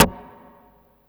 45 SD 1   -L.wav